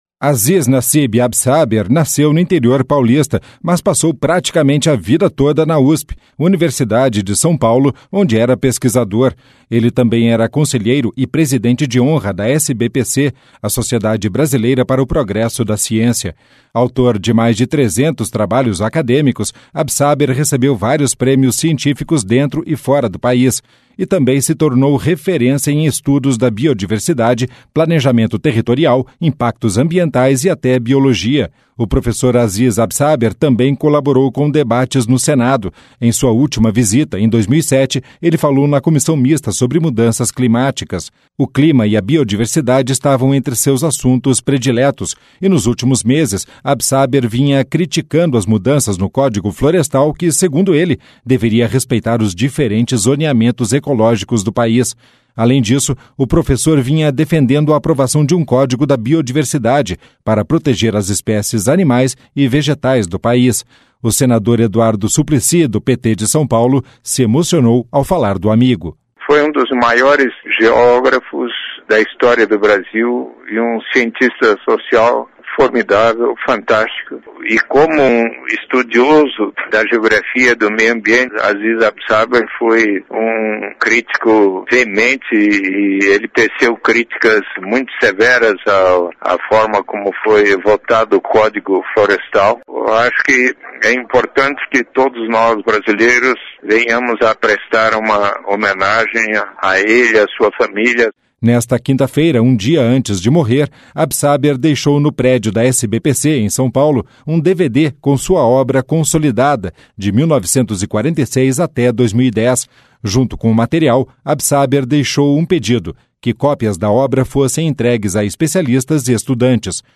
O senador Eduardo Suplicy, do PT de São Paulo, se emocionou ao falar do amigo: (Eduardo Suplicy) Foi um dos maiores geógrafos da história do Brasil e um cientista social fantástico.